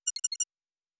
pgs/Assets/Audio/Sci-Fi Sounds/Interface/Data 12.wav at master